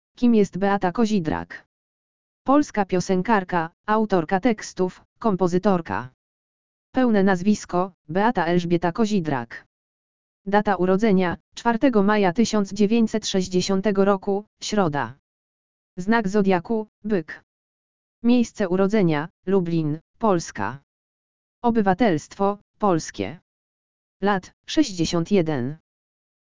audio_lektor_urodziny_beaty_kozidrak.mp3